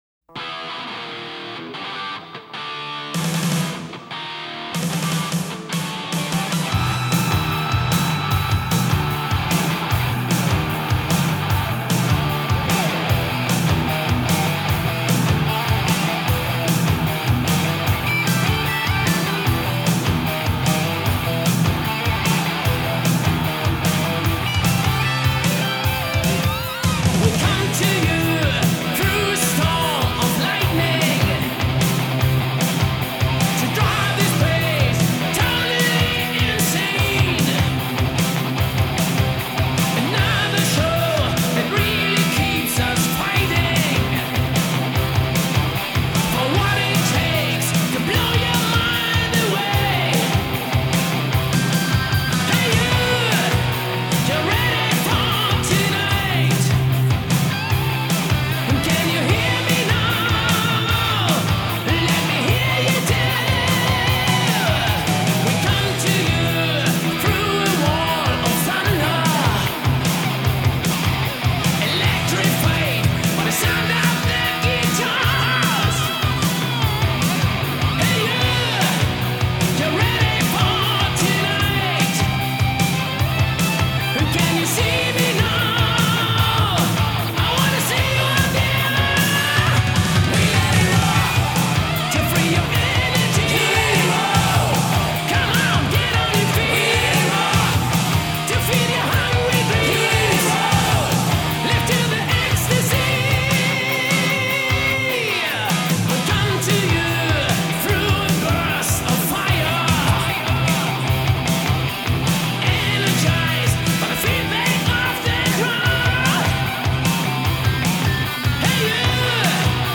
At first, I was a little dismayed by the production.